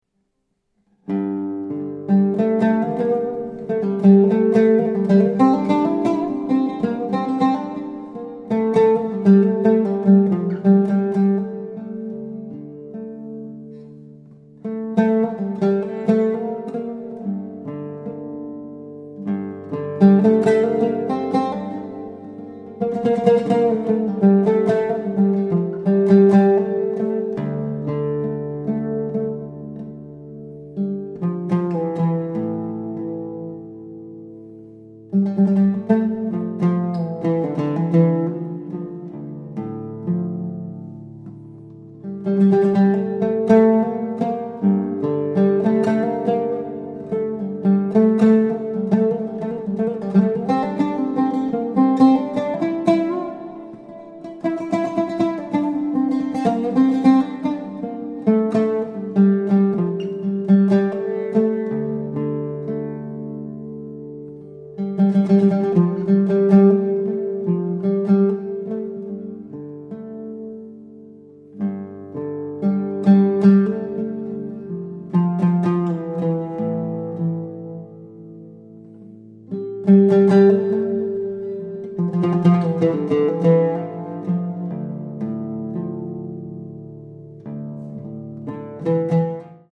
oud